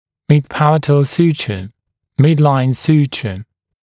[mɪd’pælətl ‘s(j)uːʧə] [‘mɪdlaɪn ‘s(j)uːʧə][мид’пэлэтл ‘с(й)у:чэ] [‘мидлайн ‘с(й)у:чэ]срединный шов верхней челюсти, небный шов